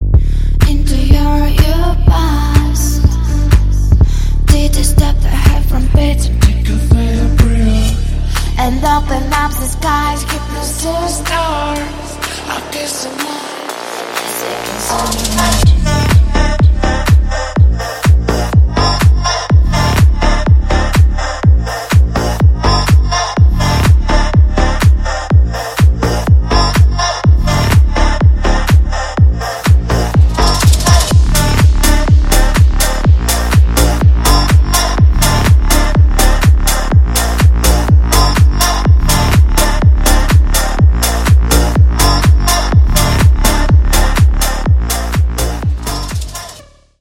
Танцевальные рингтоны / Романтические рингтоны
Громкие рингтоны